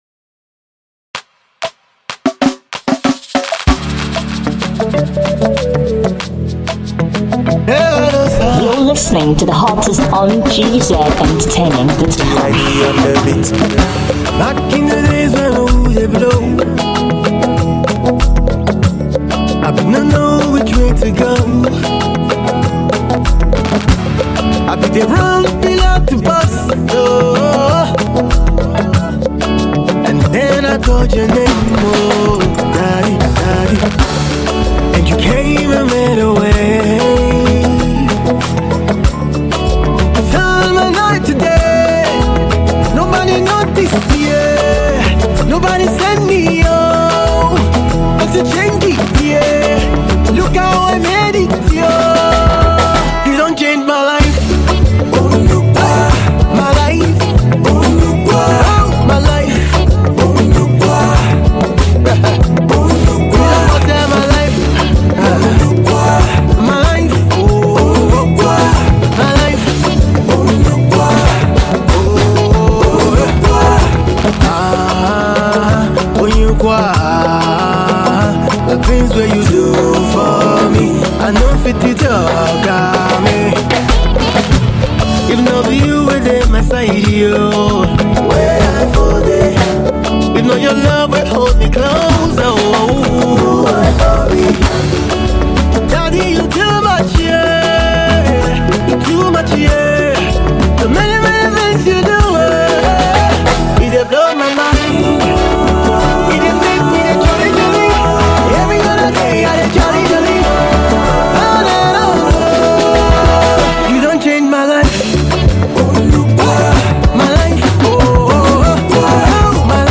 inspiring and scintillating Sound